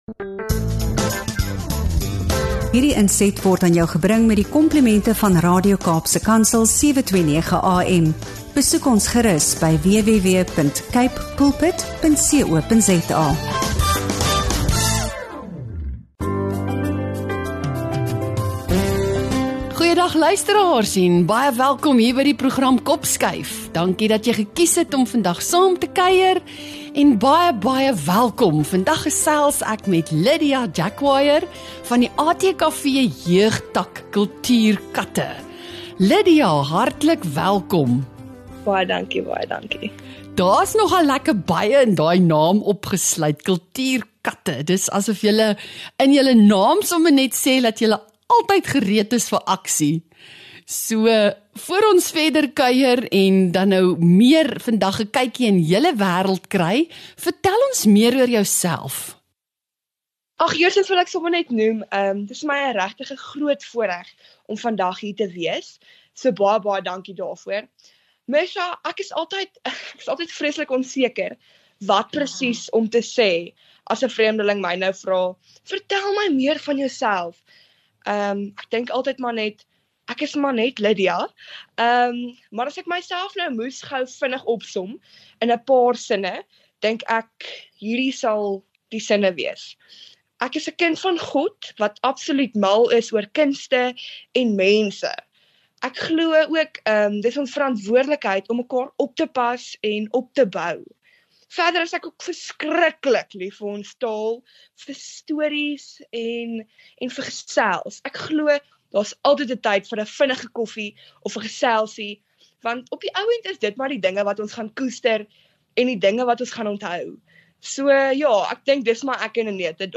In hierdie gesprek deel sy hoe die ATKV ’n onwrikbare baken is vir die krag, kultuur en kreatiwiteit van ons gemeenskappe.